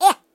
share/hedgewars/Data/Sounds/voices/Mobster/Ow4.ogg
Ow4.ogg